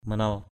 /mə-nʌl/ manal mqL [Cam M] 1.